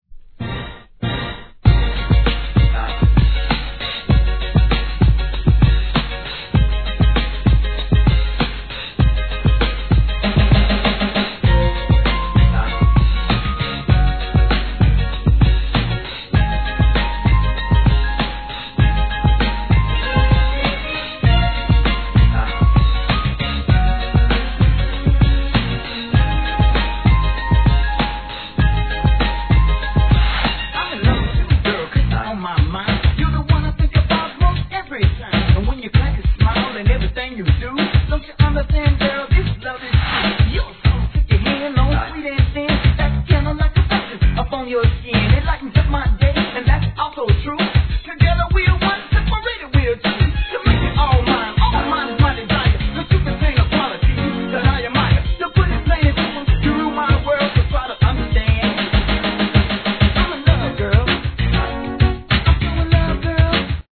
HIP HOP/R&B
グランドビート〜NEW JACK SWINGの大ヒット!